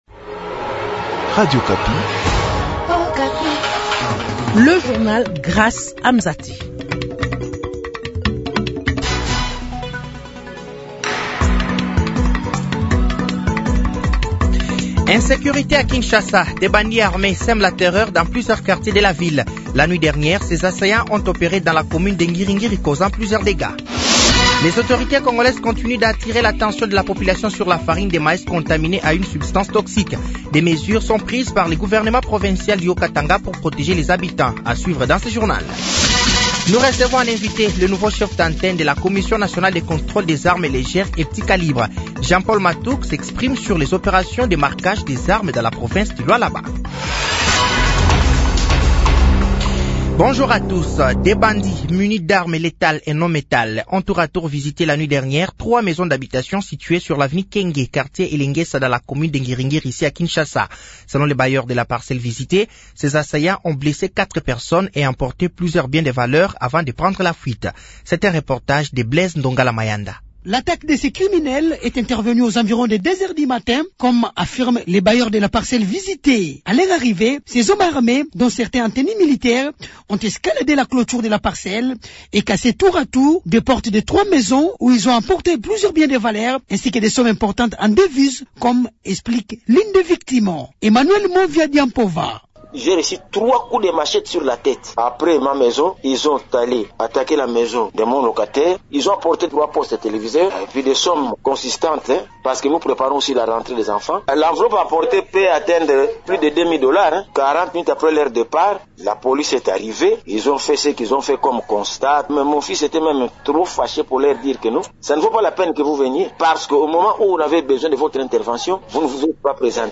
Journal de 15h
Journal français de 15h de ce jeudi 29 août 2024